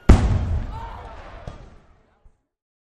烟花声效
fireworks-bang.mp3